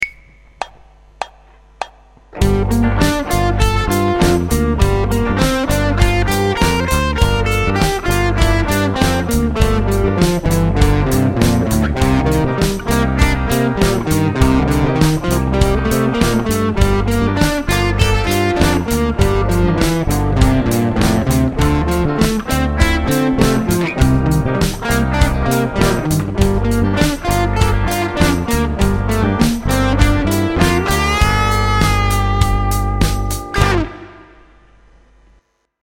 In the following example the G minor 9, C minor 9 and D minor 9 arpeggios will be used with the G and C Dorian modes. This exercise can be used to practice playing arpeggio patterns and modes over a G minor blues.
While the previous example was a great exercise it is rhythmically too rigid (using just 8th notes) to be a truly expressive guitar solo.
minorblues_exercise.mp3